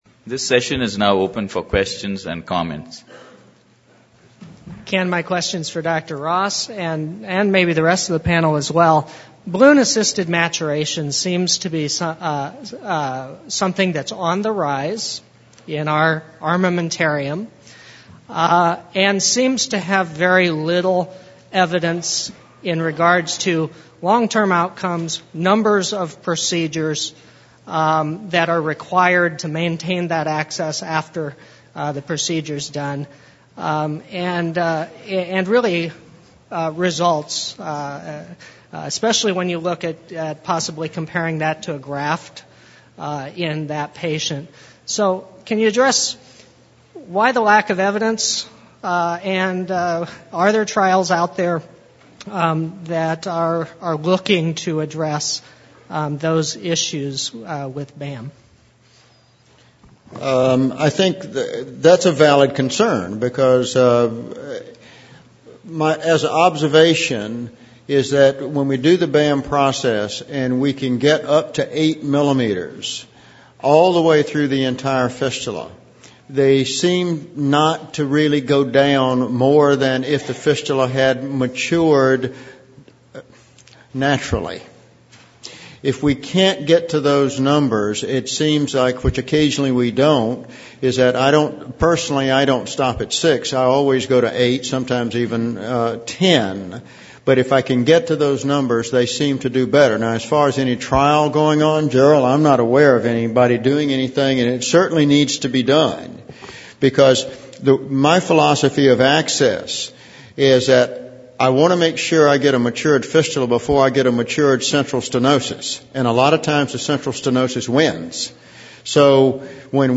HDCN-- 2011 ASDIN Annual Scientific Meeting
Discussions